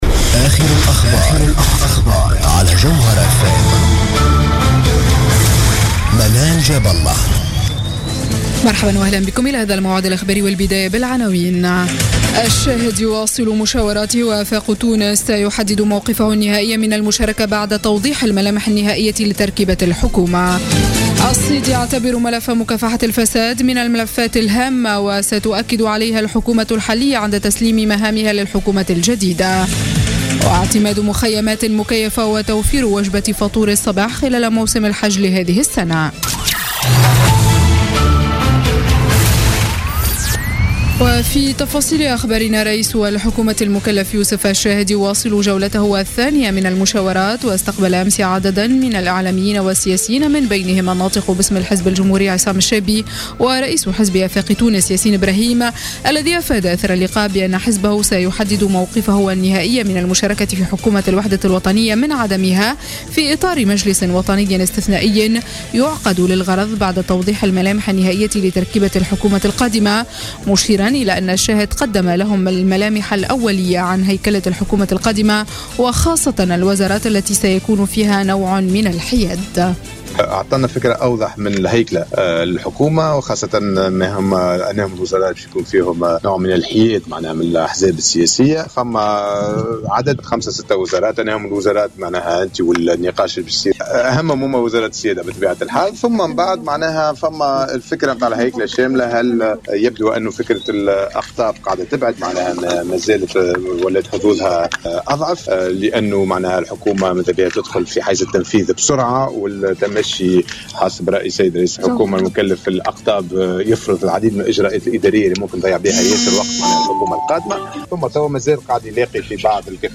نشرة أخبار منتصف الليل ليوم الجمعة 19 أوت 2016